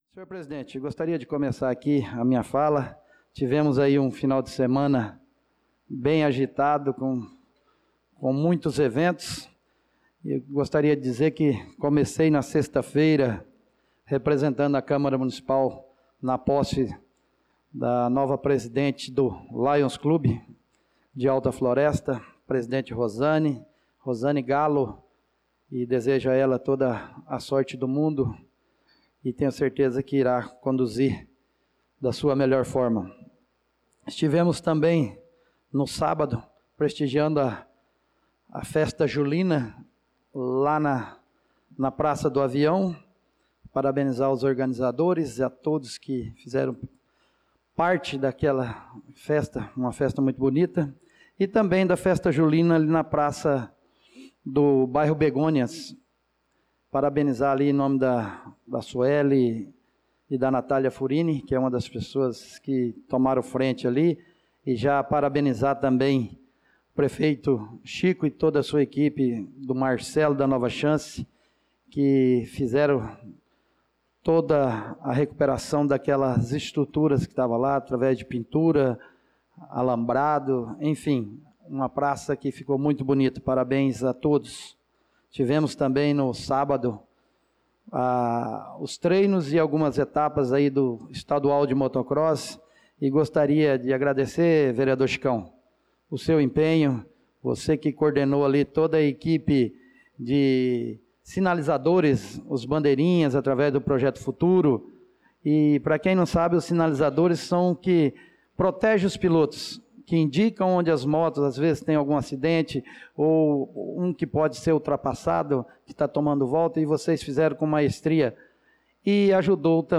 Pronunciamento do vereador Marcos Menin na Sessão Ordinária do dia 07/07/2025.